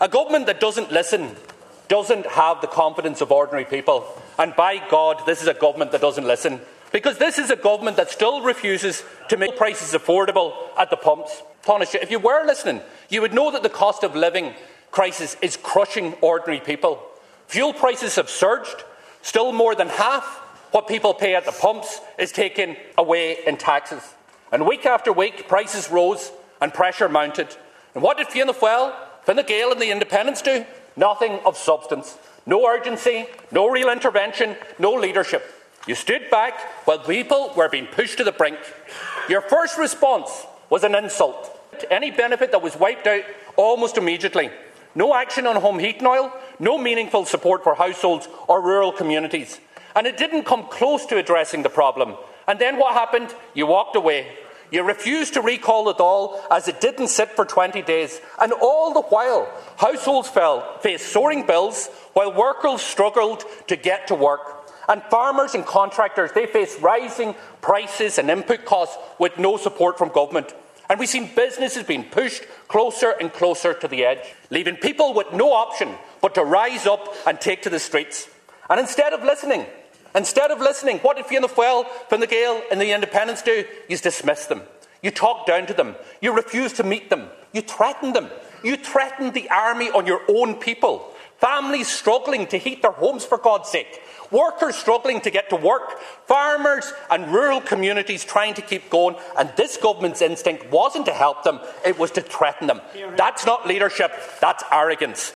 Speaking during this afternoon’s confidence motion in the Dail, he said the government had not listened to the people, and when they arose in desperation, the response was to threaten them………….